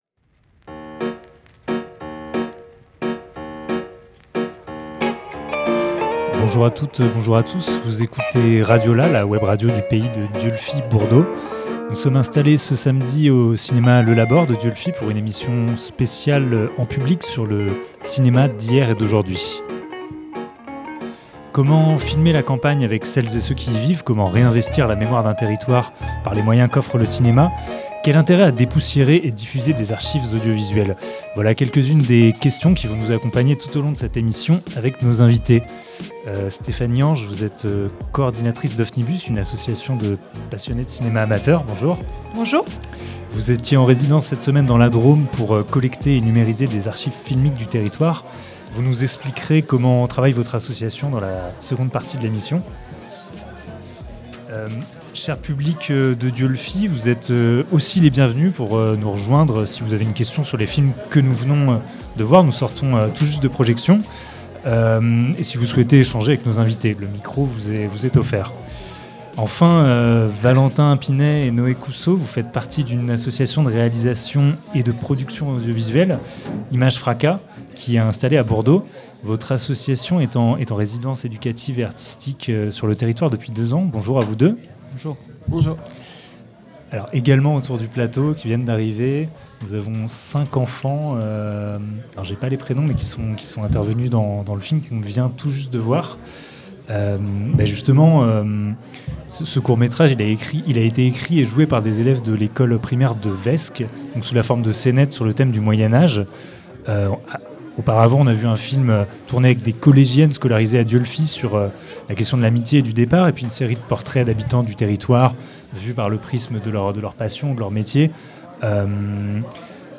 C’était aussi un plateau radio en public, animé par RadioLà en présence des réalisateurs, des archivistes, des déposants d’archives audiovisuelles, d’un musicien et de celles et ceux qui ont rendu possible cette projection, samedi 17 septembre 2022.